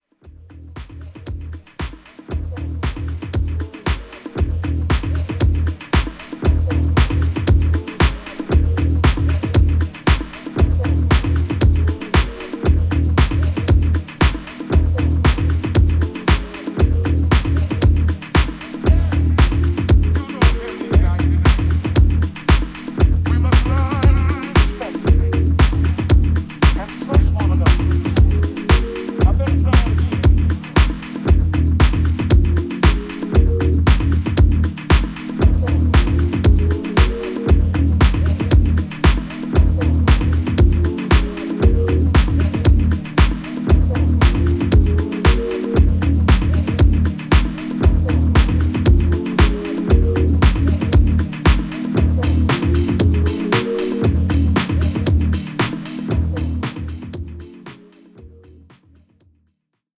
ホーム ｜ HOUSE / TECHNO > HOUSE